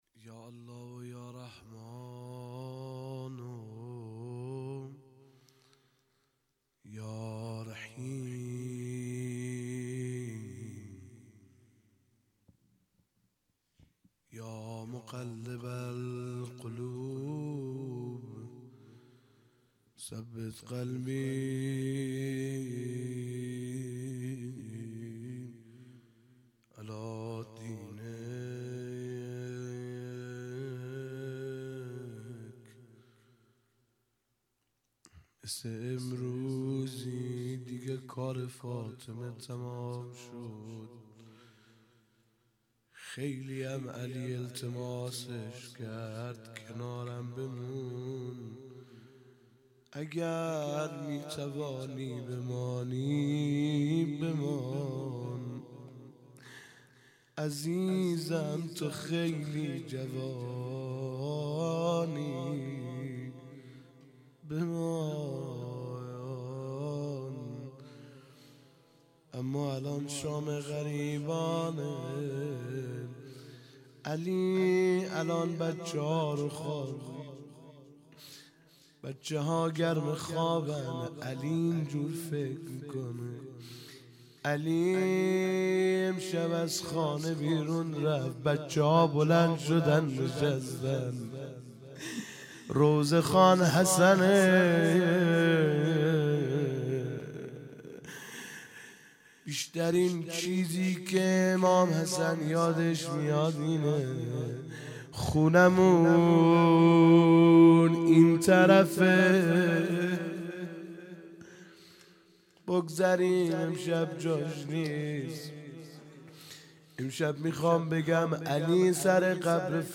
هیئت دانشجویی فاطمیون دانشگاه یزد